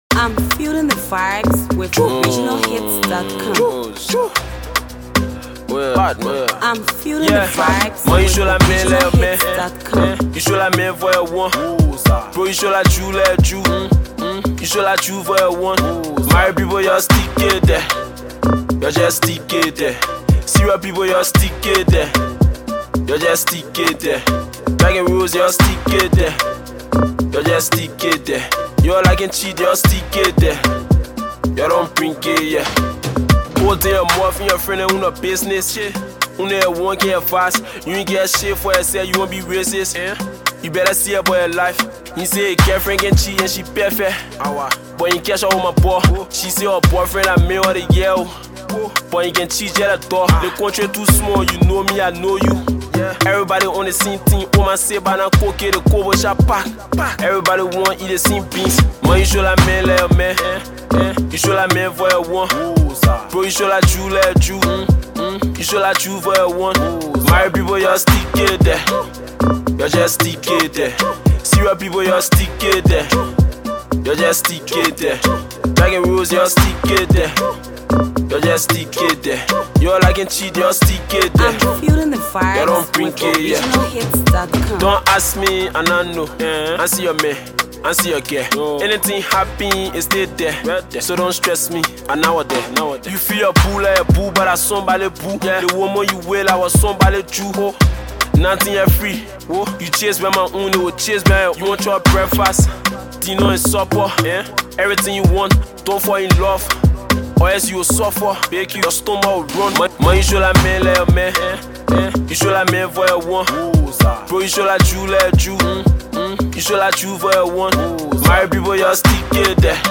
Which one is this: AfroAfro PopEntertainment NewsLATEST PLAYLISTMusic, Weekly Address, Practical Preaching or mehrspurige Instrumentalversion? AfroAfro PopEntertainment NewsLATEST PLAYLISTMusic